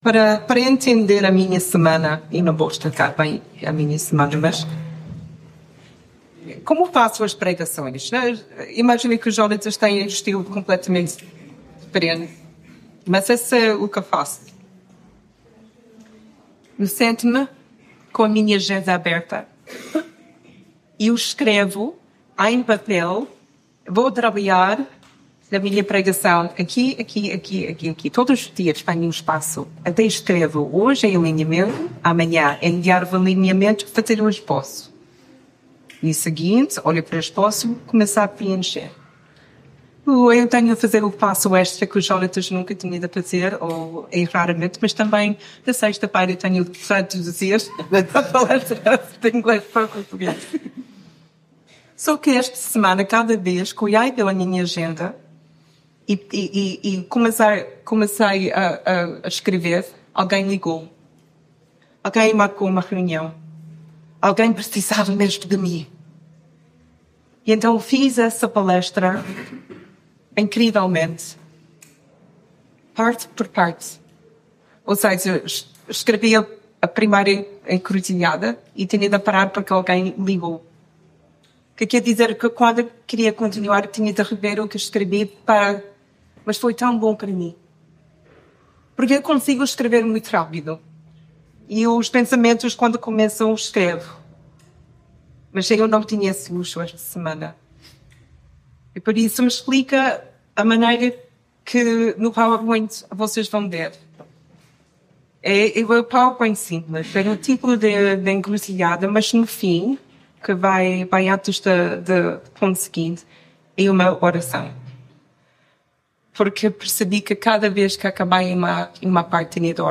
mensagem bíblica O que te vem à cabeça quando pensas em encruzilhadas?